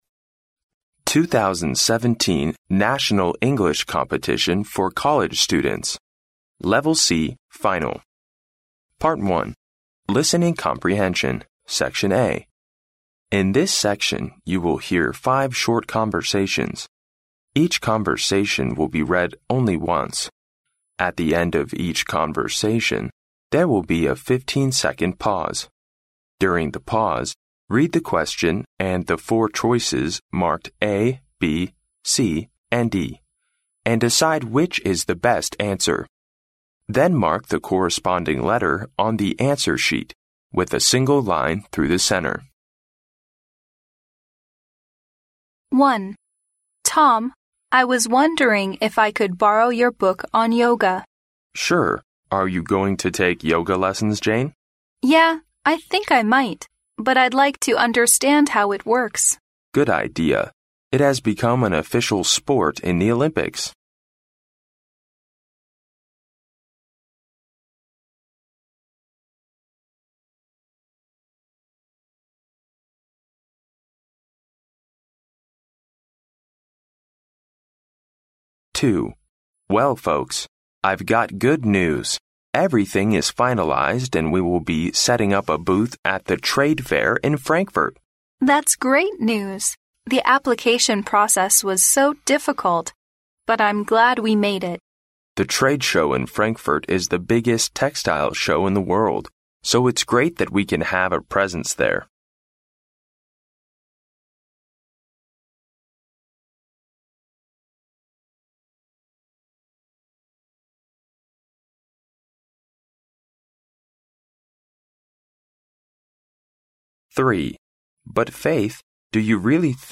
In this section, you will hear five short conversations. Each conversation will be read only once. At the end of each conversation, there will be a fifteen-second pause.